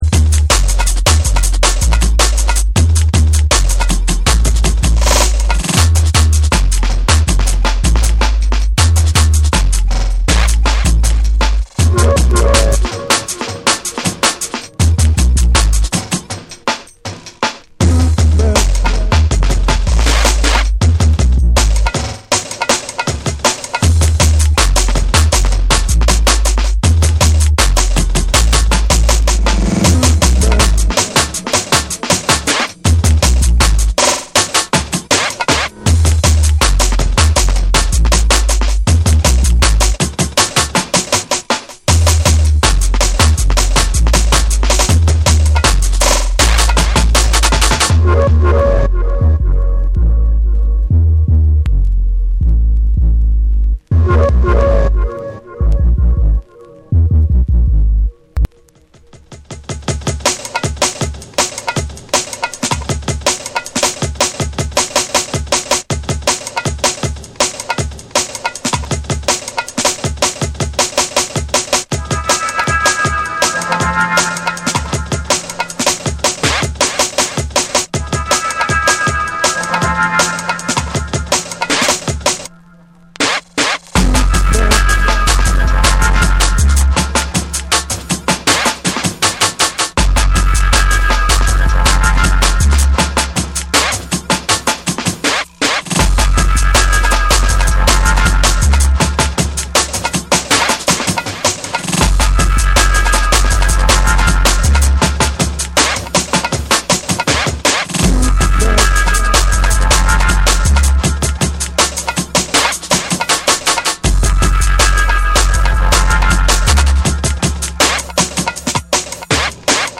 荒々しいトライバルなリズムとダークなベースが駆け抜ける